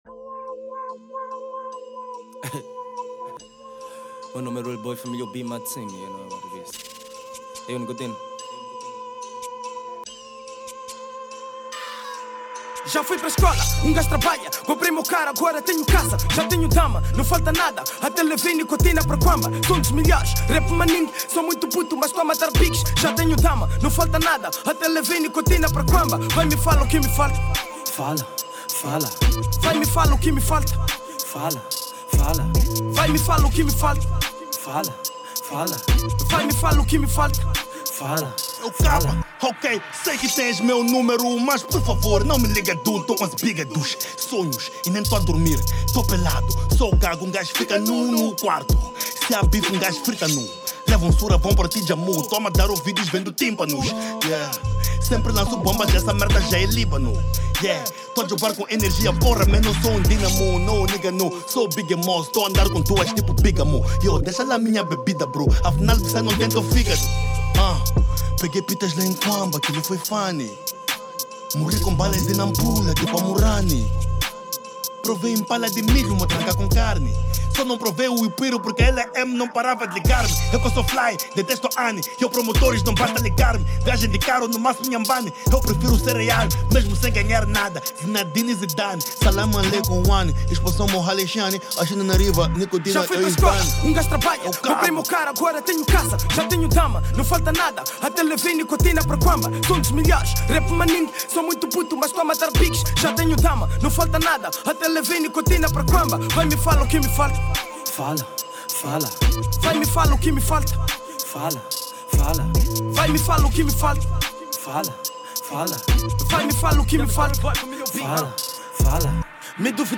” Hip-Hop Rap 2021 ”Download Mp3” 320kbps